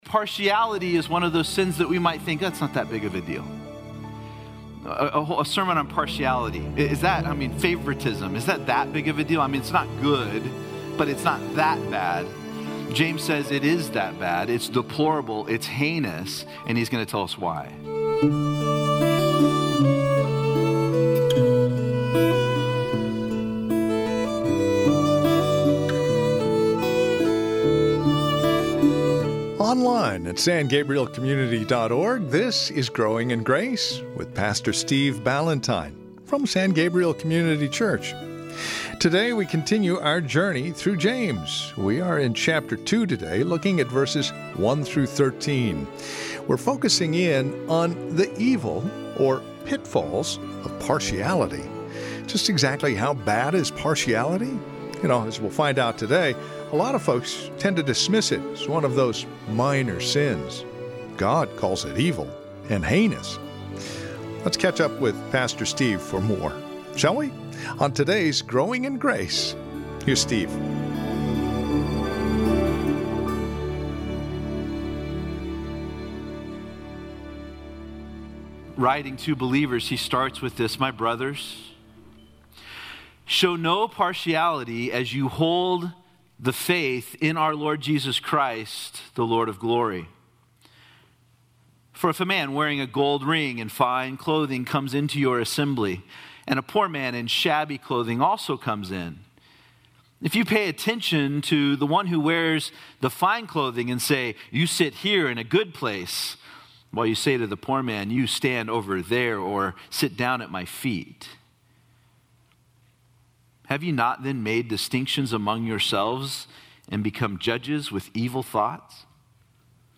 Partiality is one of those sins that we might think is not that big of a deal. A sermon on partiality, favoritism, it's not good but it is not that bad. James says it is that bad.